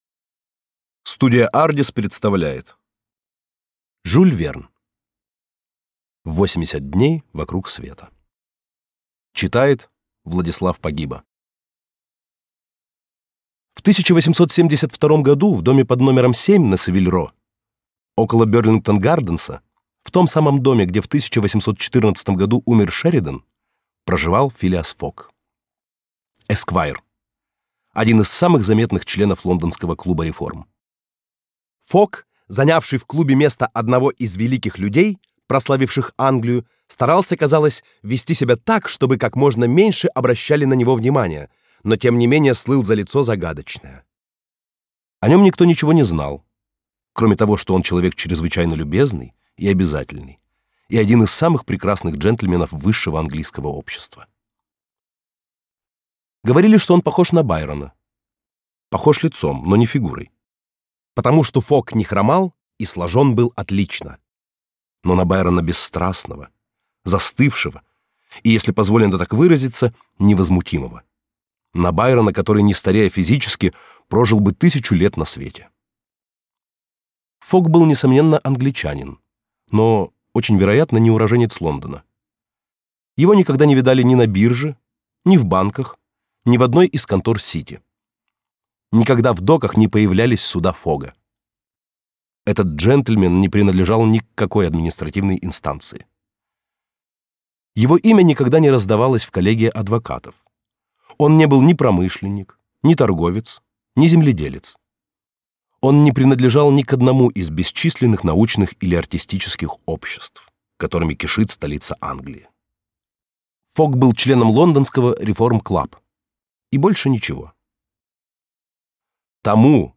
Аудиокнига 80 дней вокруг света | Библиотека аудиокниг